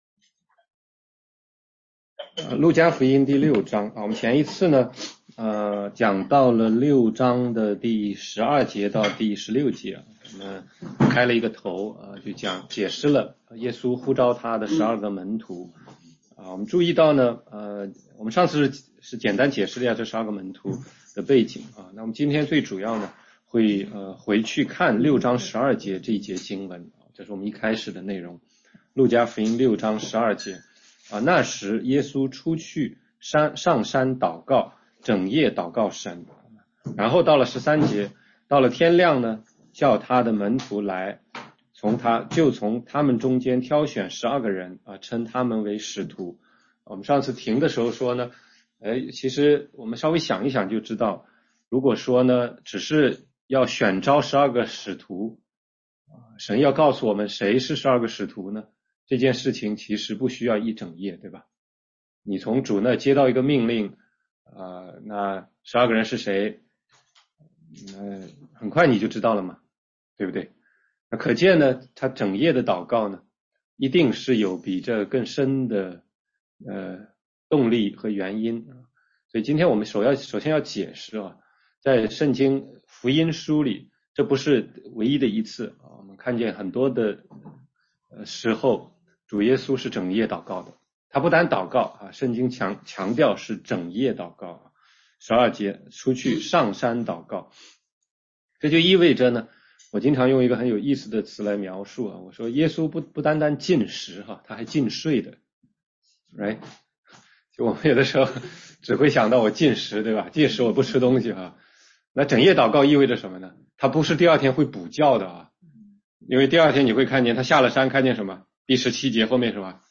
16街讲道录音 - 路加福音6章17-26节：四福四祸（上）
全中文查经